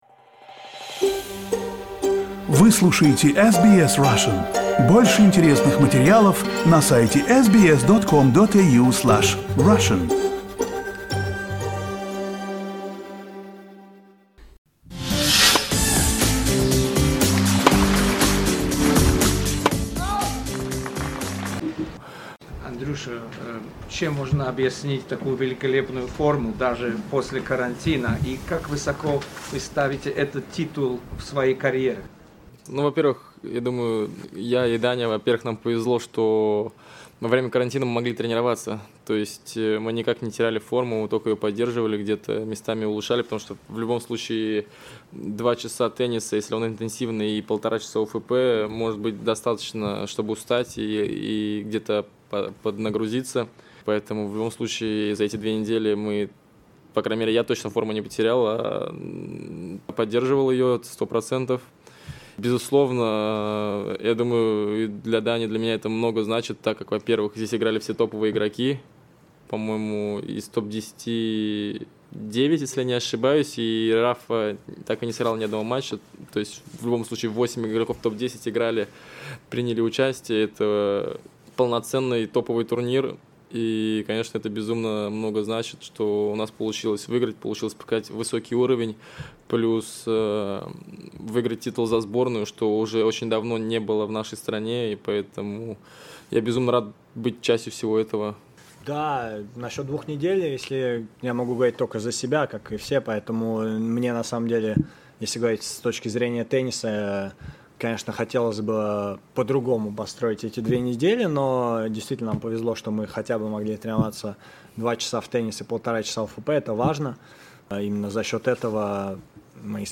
Interview with the Russian tennis players Medvedev, Rublev and Donskoy after winning the ATP Cup.